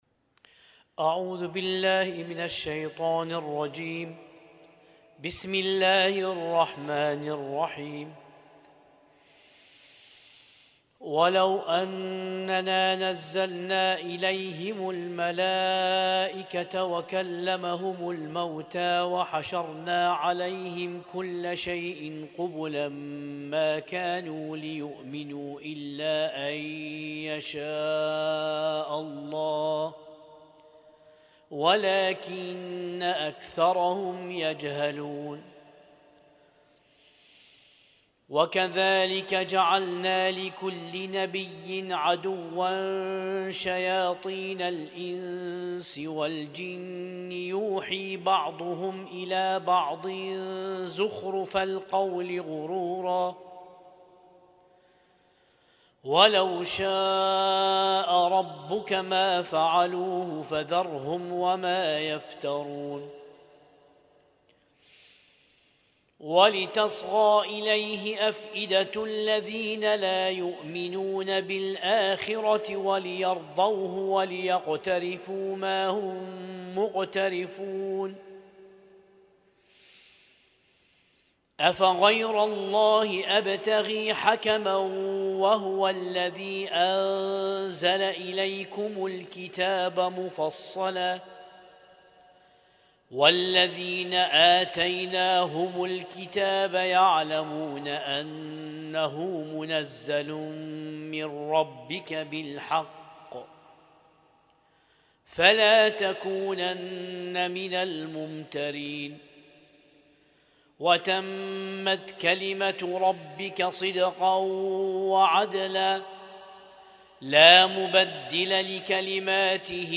قائمة المـكتبة الصــوتيه القران الكريم - الجزء الثامن
Husainyt Alnoor Rumaithiya Kuwait